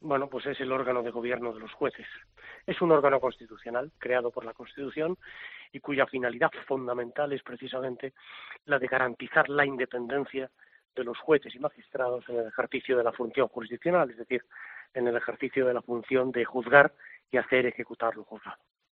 abogado y profesor universitario